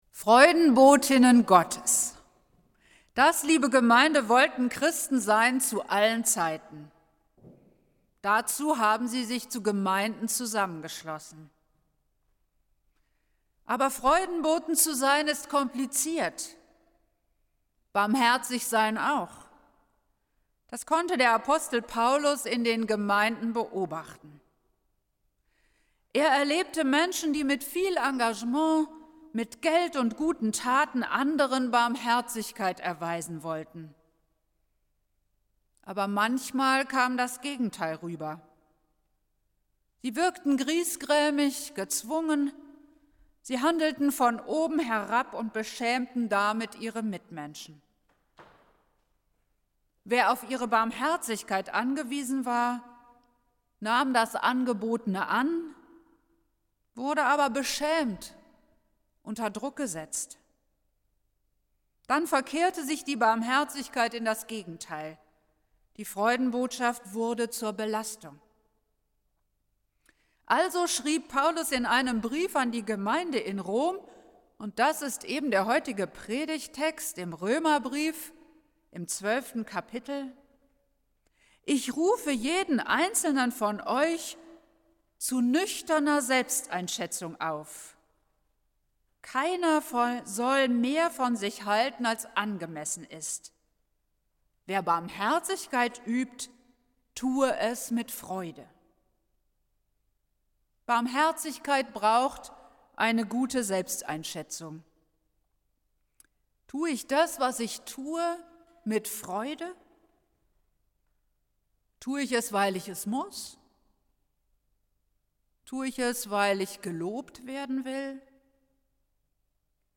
Predigt des Gottesdienstes aus der Zionskirche vom Sonntag, 10.01.2021